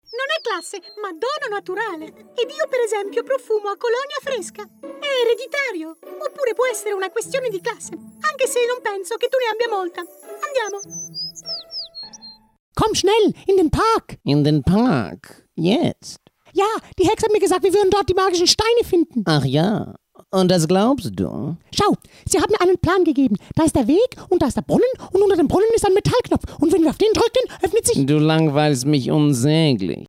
Animation Reel
Playing age: 30 - 40sNative Accent: French, German, International, SpanishOther Accents: French, German, Italian, Russian, Spanish
He has a versatile voice ranging from a reassuring warm baritone to youthful high-energy delivery and has an exceptional ear for accents and character voices.
He has a professionally equipped home studio for remote recording.